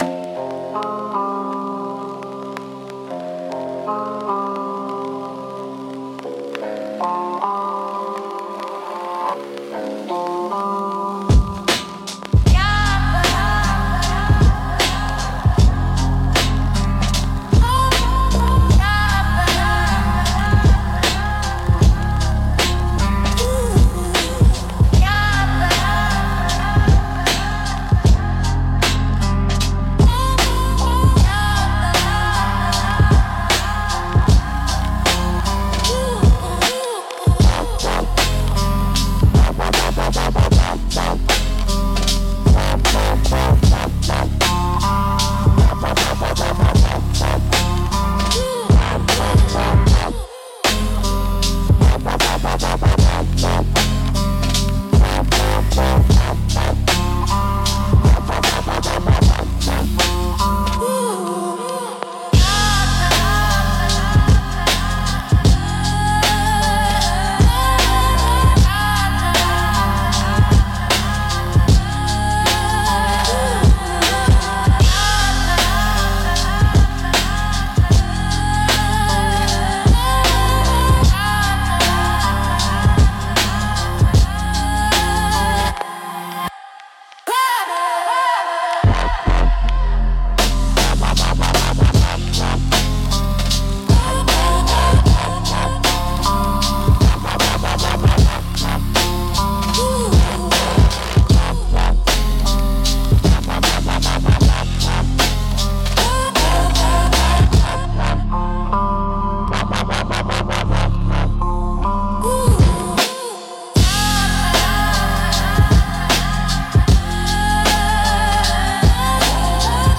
Instrumental - The Hum of Hungry Lamps